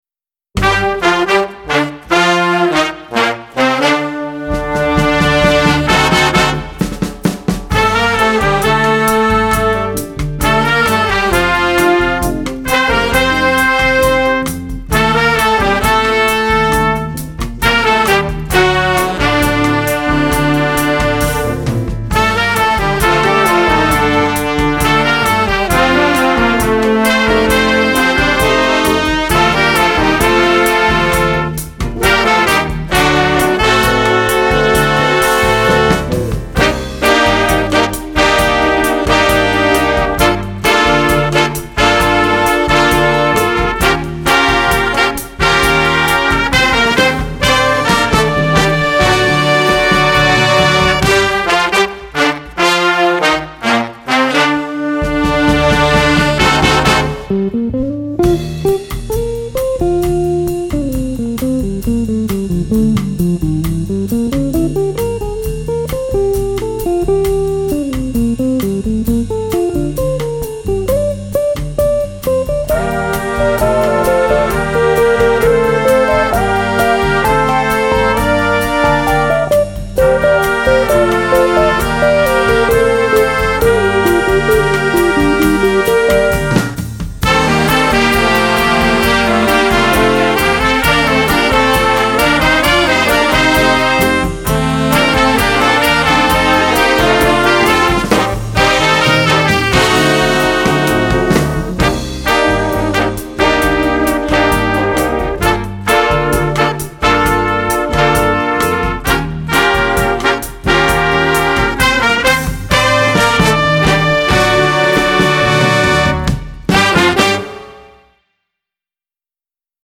Voicing: Flex Jazz